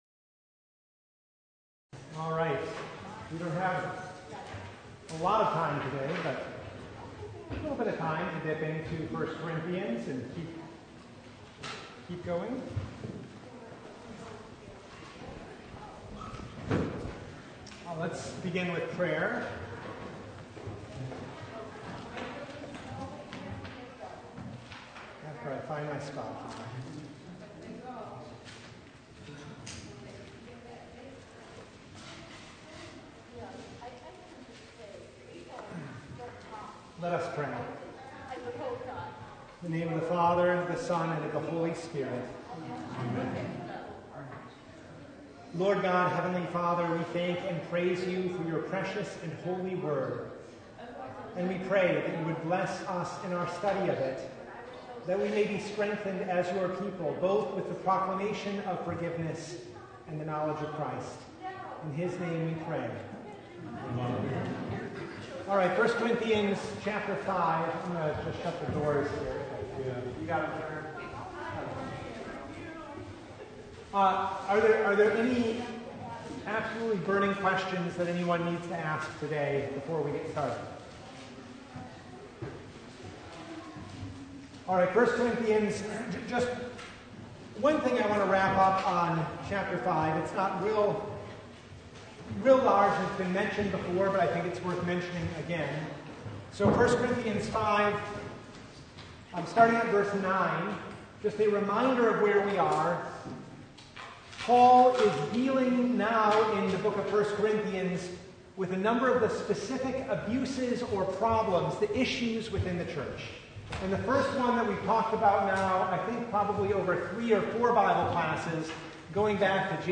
1 Corinthians 5:9-13 Service Type: Bible Hour Topics: Bible Study « The Transfiguration of Our Lord